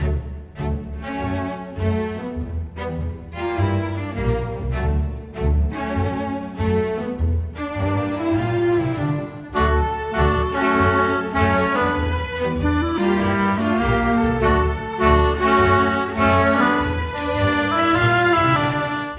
Rock-Pop 10.wav